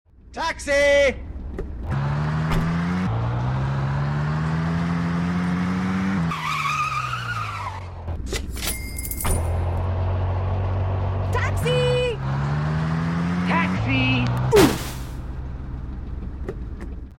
After discovering the Suno AI music generation tool, I was absolutely blown away.
If you’re curious, you can listen to a small selection of the tracks I eventually included in the game here.